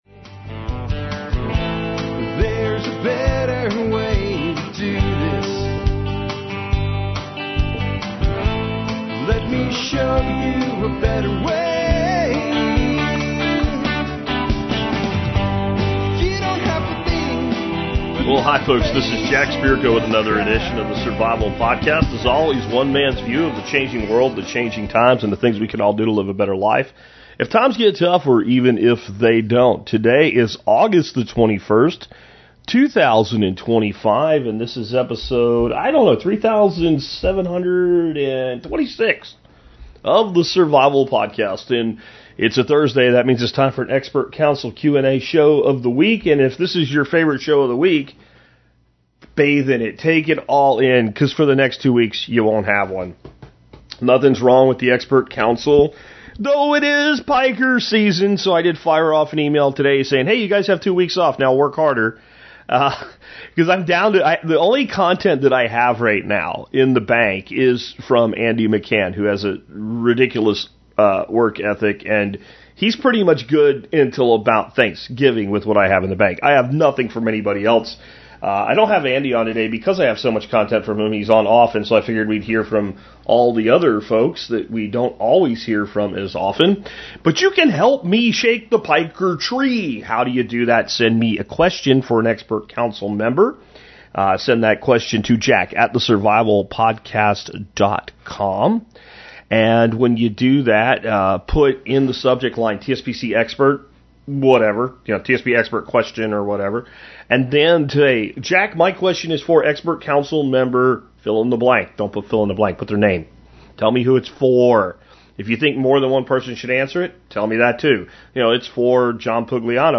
1 ALLISON MACK: From Smallville to Cult Scandal & Taking Accountability for It Today 1:15:20 Play Pause 2h ago 1:15:20 Play Pause Play later Play later Lists Like Liked 1:15:20 Allison Mack (Smallville, Wilfred) joins us for one of the most raw and reflective conversations we've ever had... After years away from the public eye, Allison opens up about her journey from fame and ambition to manipulation and accountability, sharing how she rebuilt her identity after the collapse of everything she knew.